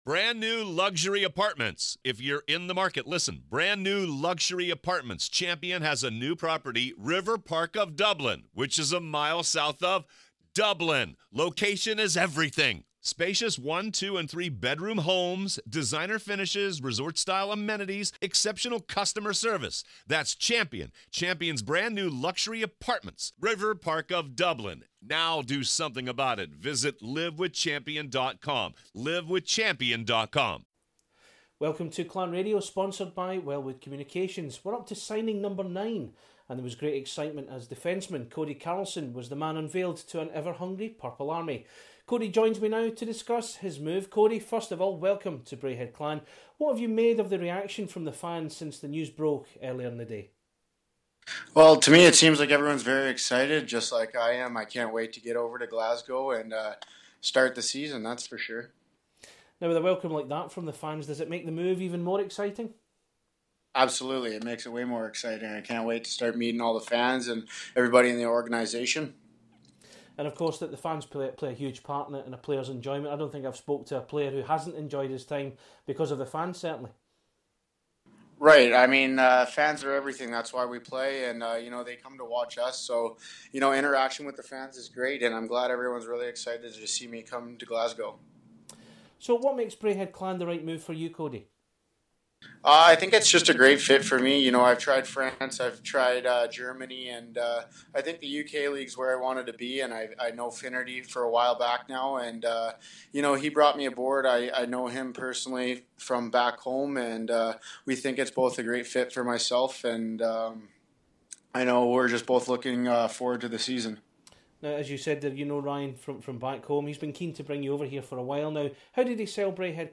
Clan Chat / INTERVIEW